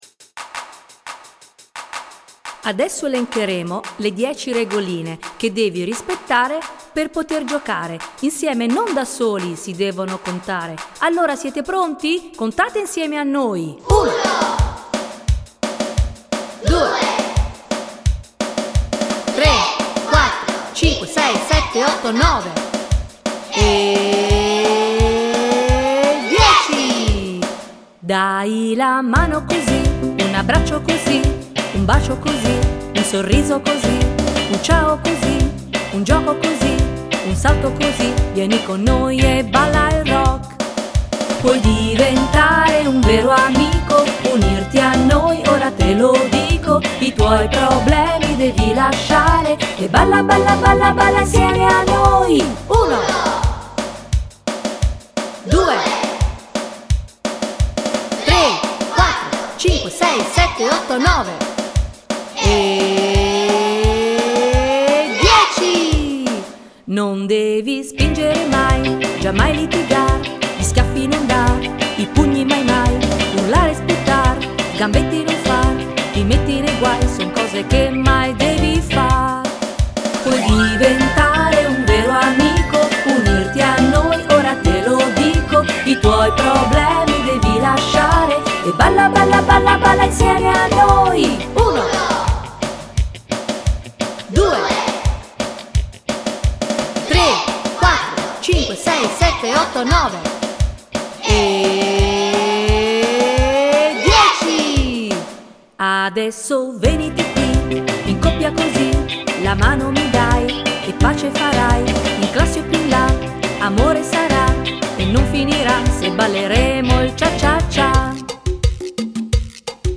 Una volta elaborato il testo definitivo e realizzata la base musicale sulla quale poter cantare, ho portato a scuola un computer portatile dotato di microfono con il quale ho registrato la voce dei bambini che in coro contavano all'unisono fino a 10.
Durante la manifestazione finale del concorso, i bambini hanno potuto presentare la canzone direttamente ad Anghiari (AR) aggiudicandosi addirittura il secondo posto con il seguente giudizio: "un brano divertente, brioso dalla melodia che rimane in testa, un ritmo simpatico e travolgente che porte a ballare tutta la gente".